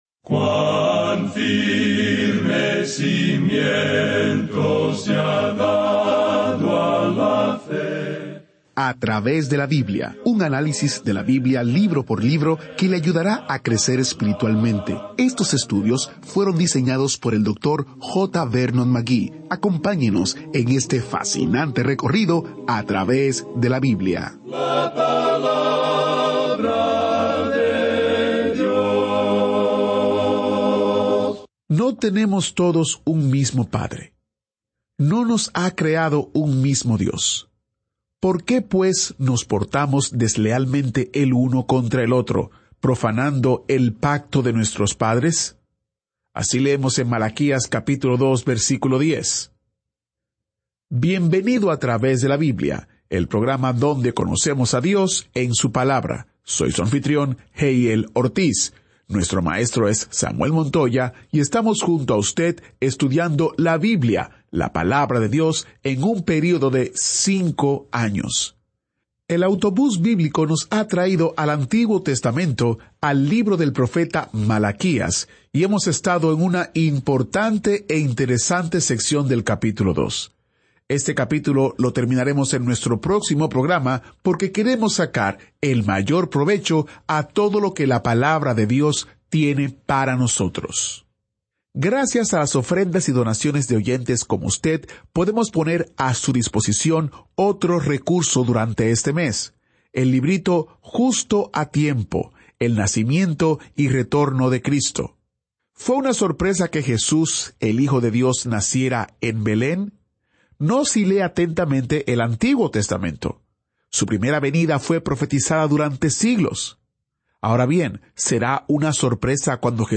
Este es un programa de radio diario de 30 minutos que sistemáticamente lleva al oyente a través de toda la Biblia.